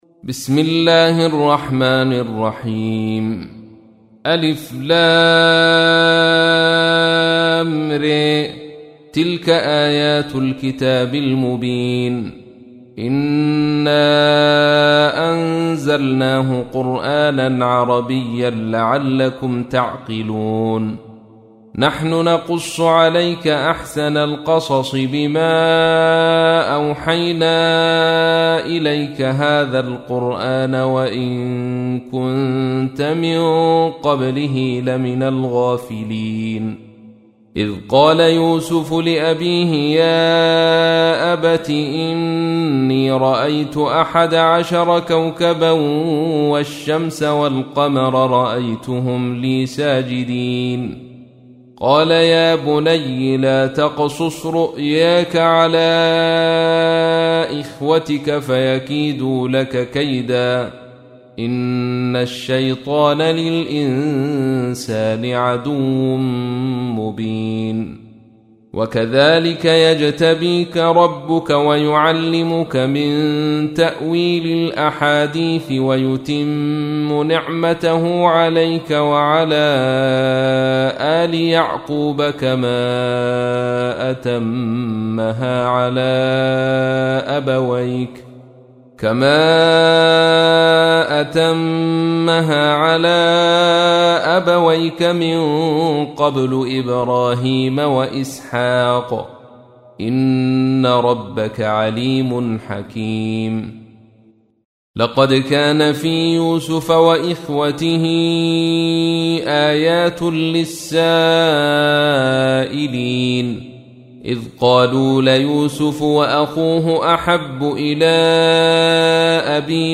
تحميل : 12. سورة يوسف / القارئ عبد الرشيد صوفي / القرآن الكريم / موقع يا حسين